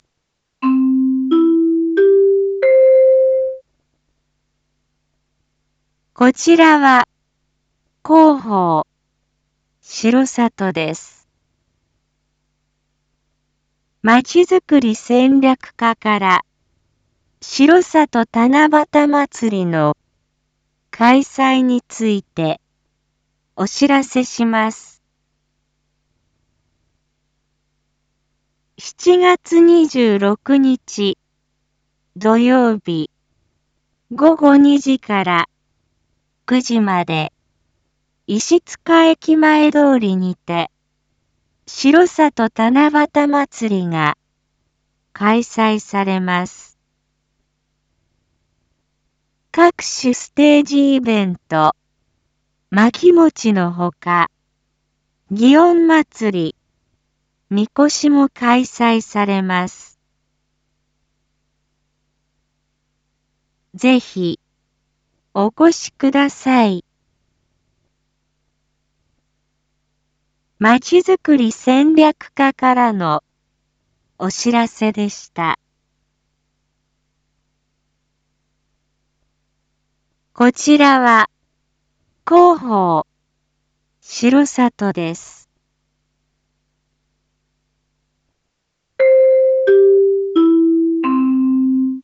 一般放送情報
Back Home 一般放送情報 音声放送 再生 一般放送情報 登録日時：2025-07-25 19:01:33 タイトル：しろさと七夕まつり2025開催 インフォメーション：こちらは広報しろさとです。 まちづくり戦略課から、しろさと七夕まつりの開催について、お知らせします。